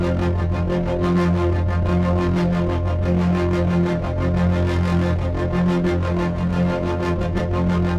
Index of /musicradar/dystopian-drone-samples/Tempo Loops/90bpm
DD_TempoDroneA_90-F.wav